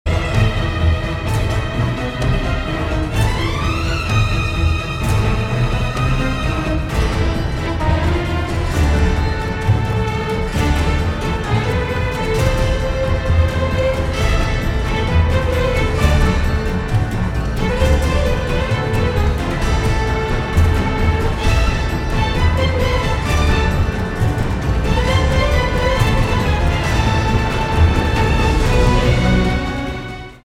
• Качество: 256, Stereo
без слов
инструментальные
классические
саундтрек